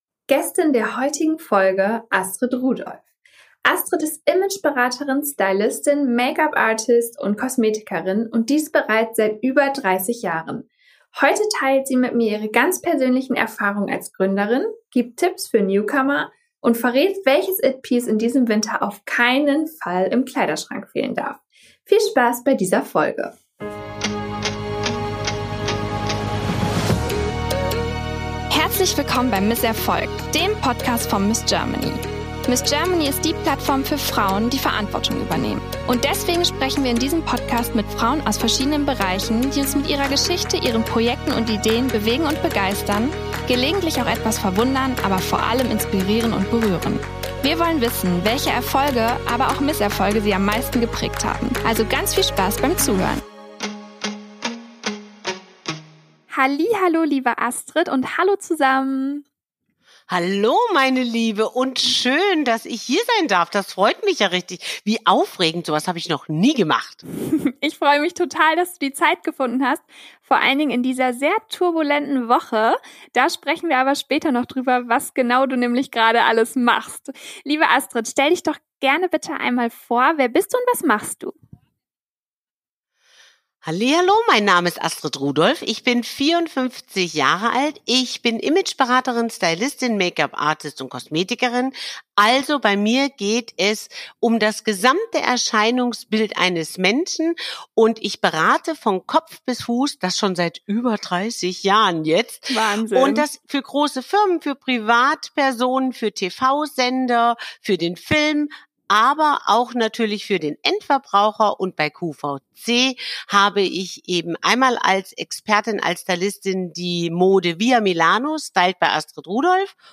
Wir sprechen bei Miss Erfolg mit interessanten Frauen über ihre Erfolge, aber genauso auch über ihre Misserfolge.